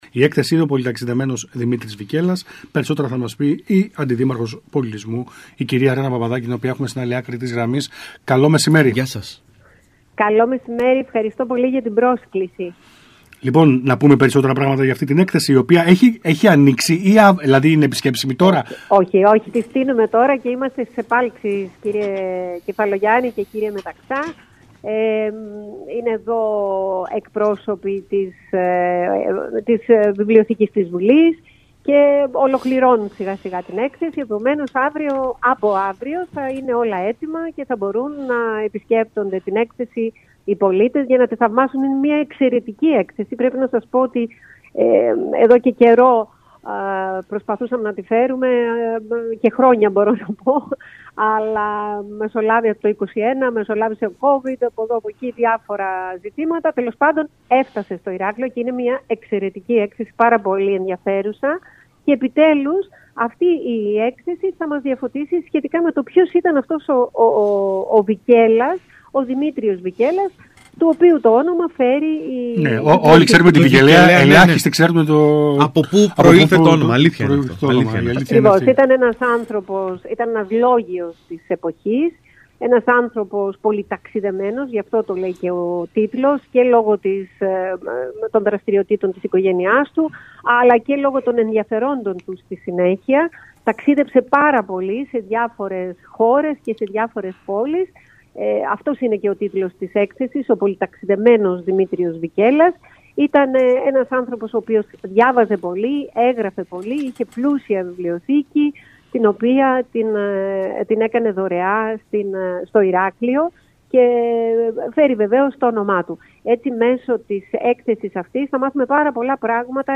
Μιλώντας στον ΣΚΑΙ Κρήτης 92,1